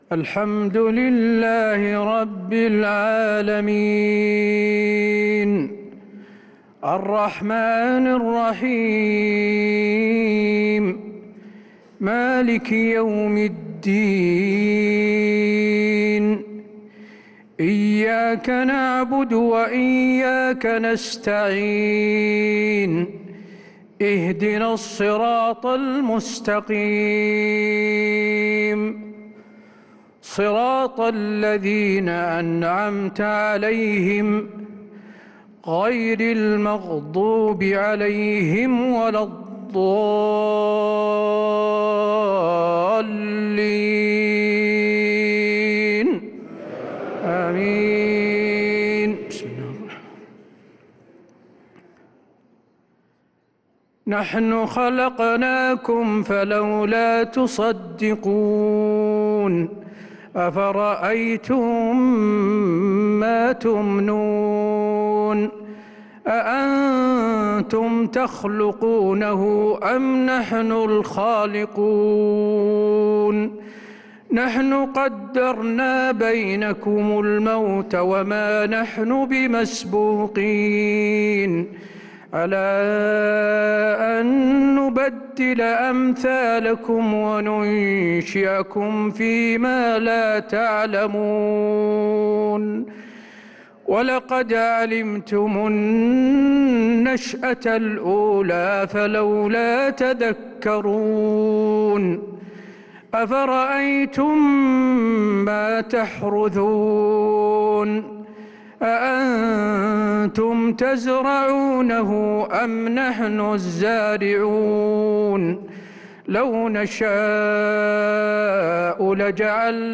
عشاء الإثنين 3-9-1446هـ خواتيم سورة الواقعة 57-96 | Isha prayer from Surat al-Waqi`ah 3-3-2025 > 1446 🕌 > الفروض - تلاوات الحرمين